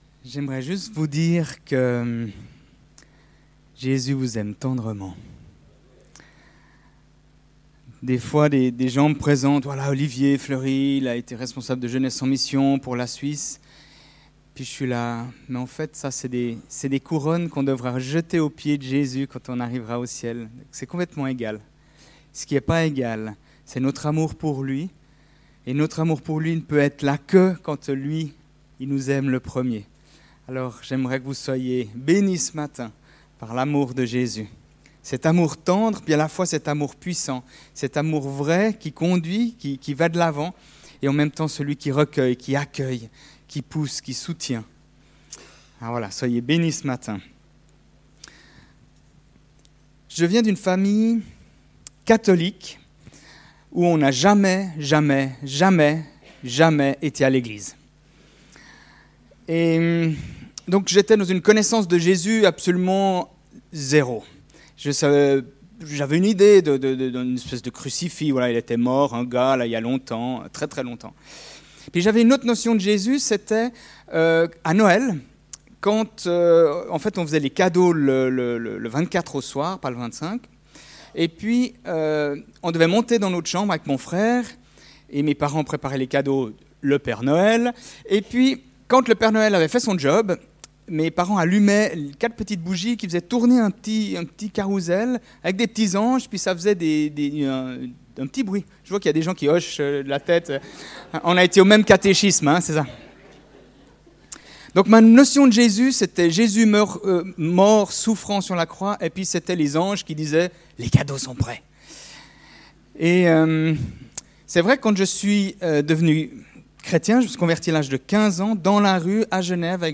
Culte du 7 octobre 2018 « Jesus Celebration 2033 »